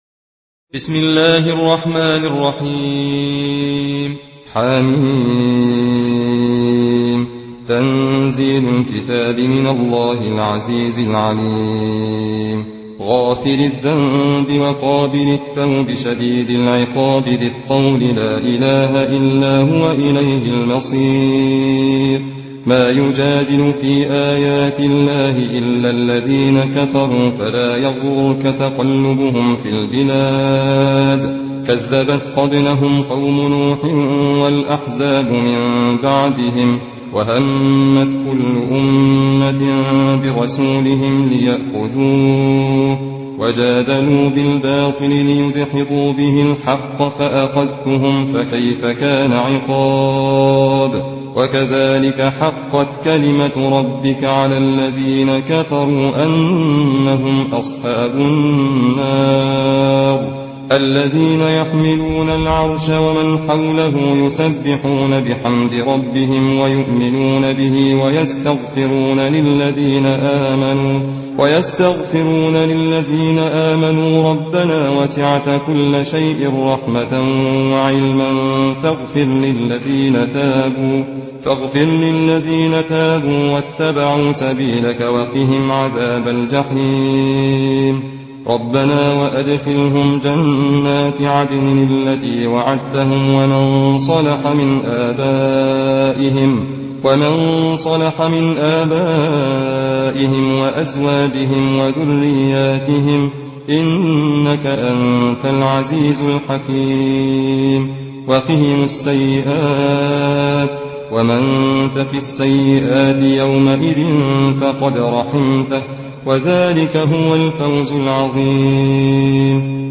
حفص عن عاصم